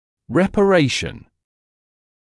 [ˌrepə’reɪʃn][ˌрэпэ’рэйшн]репаративная регенерация; репарация